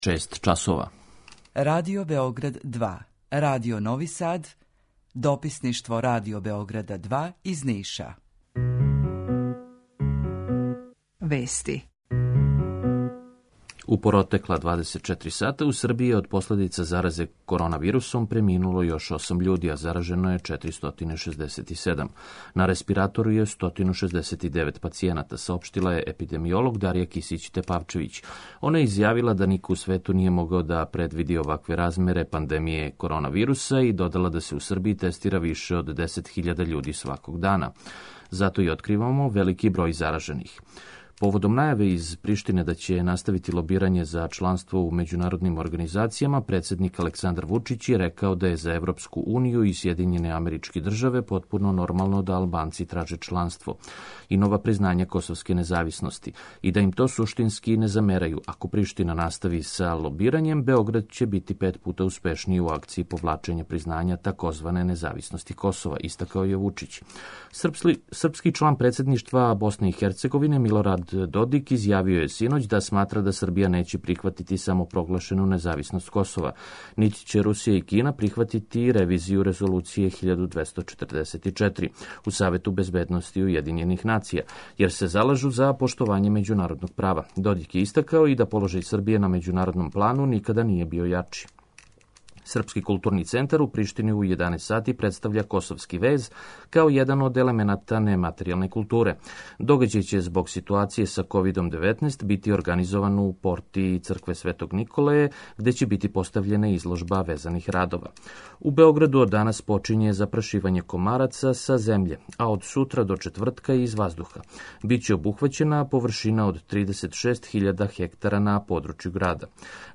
Јутарњи програм из три студија